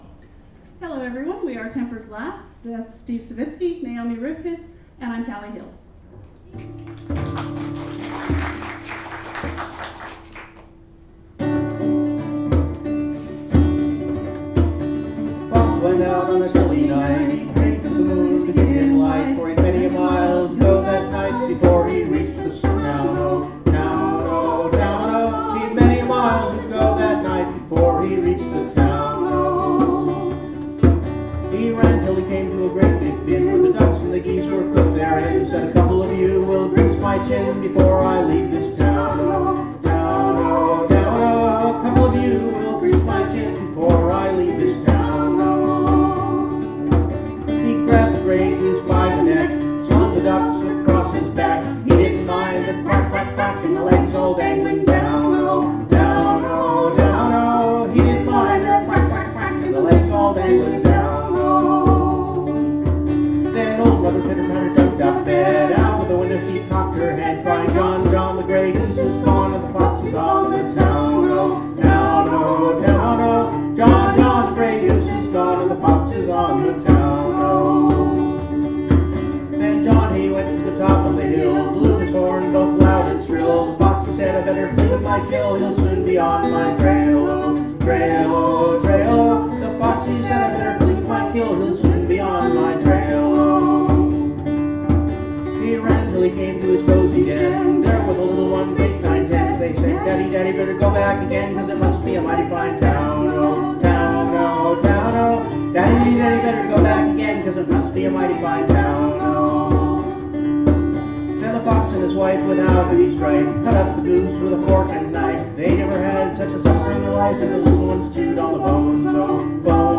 Baycon 2009